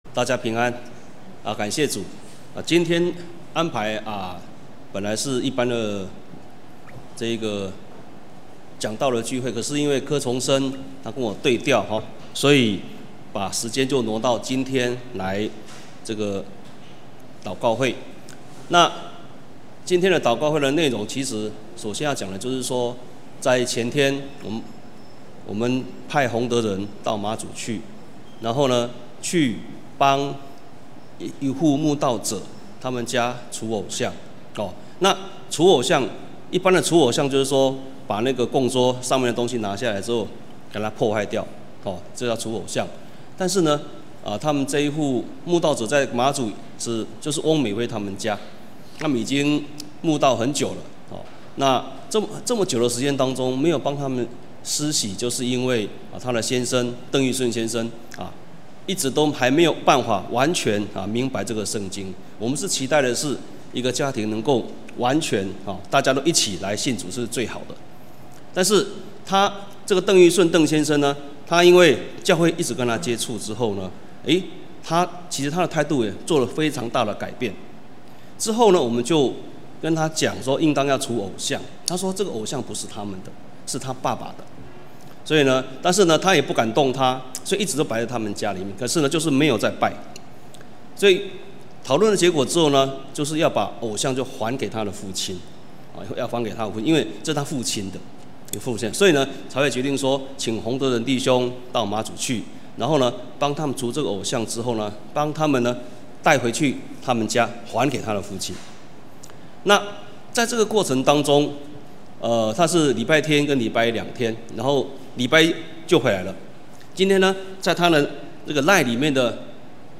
2019年1月份講道錄音已全部上線